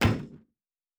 Metal Box Impact 2_2.wav